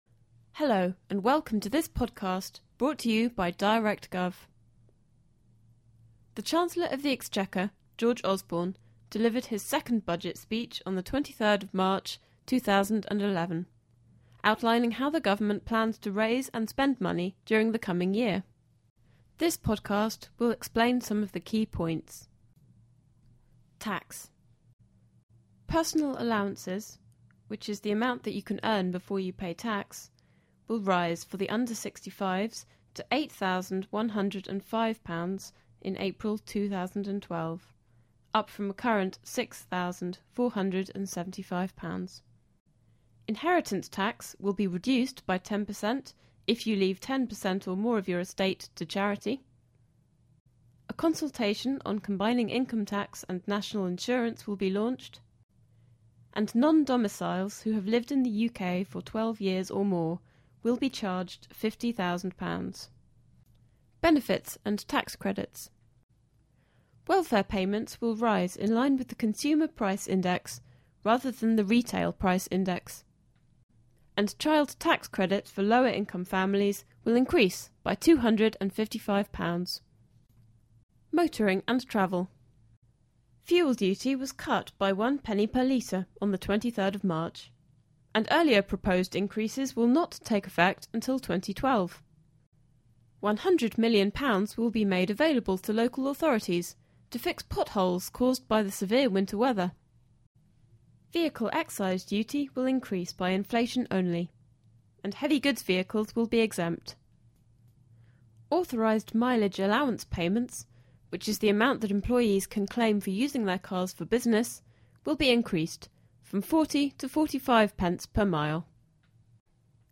A podcast with highlights of Chancellor George Osborne's Budget 2011 speech is available.